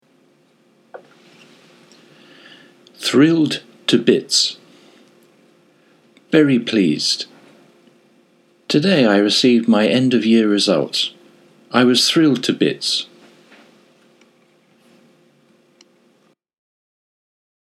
つまり、 be 動詞 ＋ thrilled to bits で、大興奮する、大喜びする、という意味になります。 英語ネイティブによる発音は下記のリンクをクリックしてください。